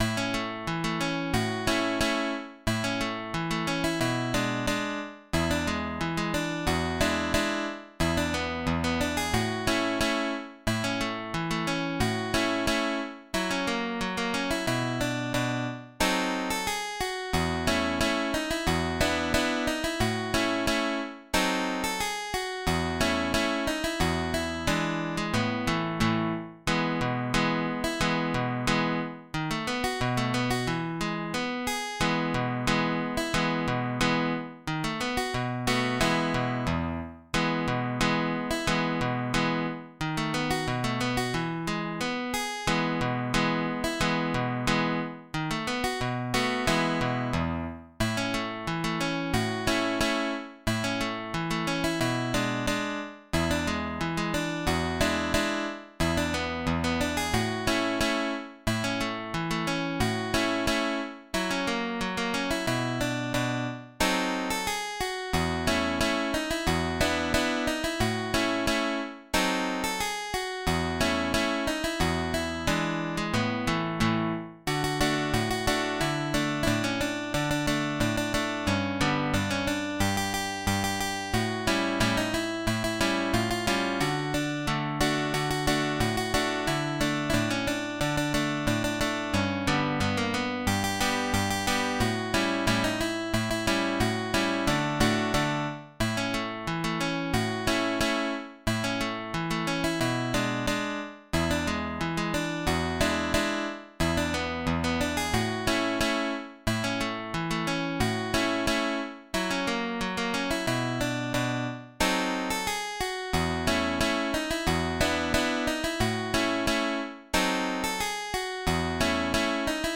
Polka***